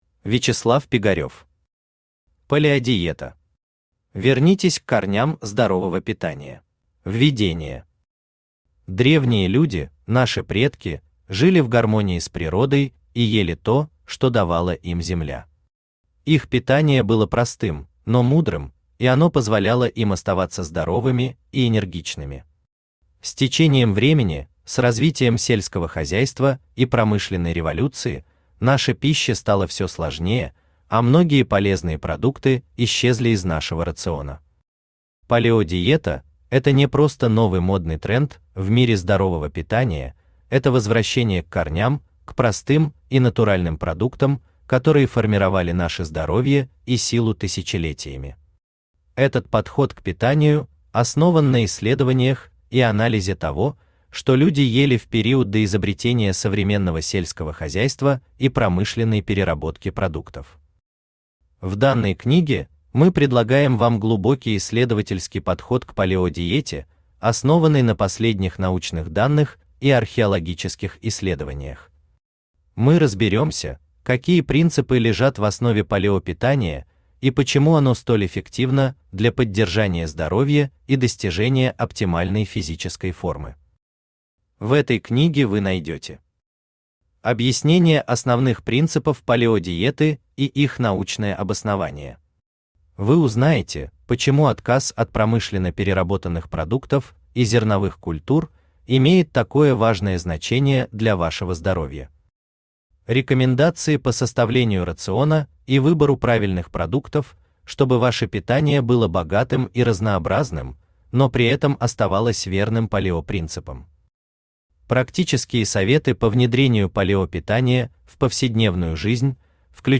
Аудиокнига Палеодиета. Вернитесь к корням здорового питания | Библиотека аудиокниг
Вернитесь к корням здорового питания Автор Вячеслав Пигарев Читает аудиокнигу Авточтец ЛитРес.